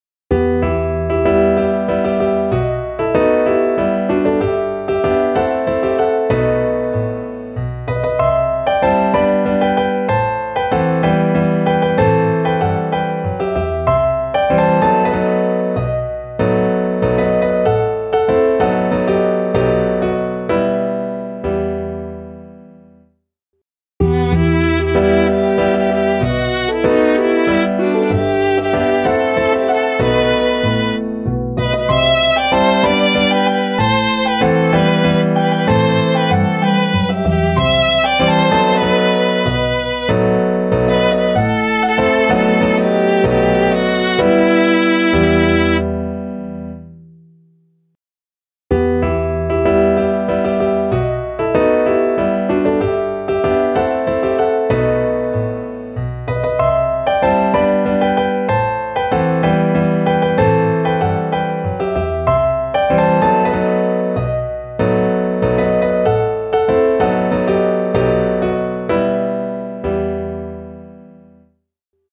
Klaviersatz
midi_der-trost-des-obristen_klavier_320.mp3